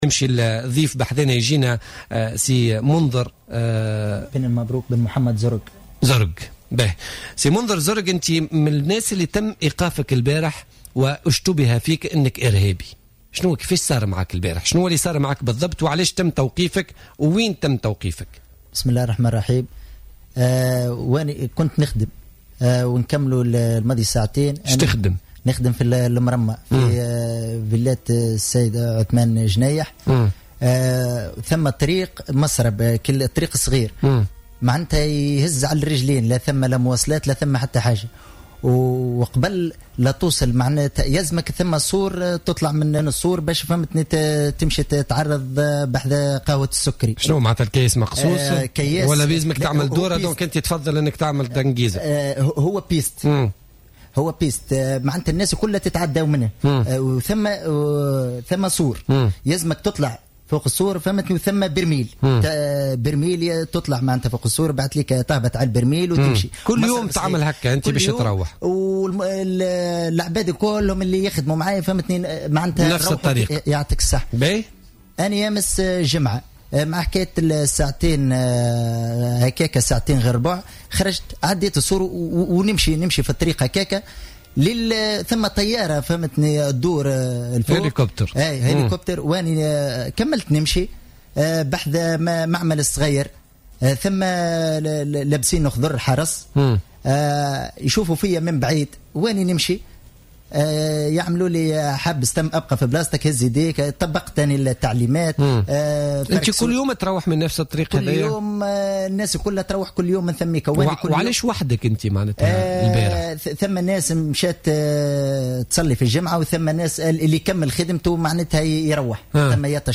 ضيفا في استوديو الجوهرة أف أم ليتحدث عن تلك اللحظات.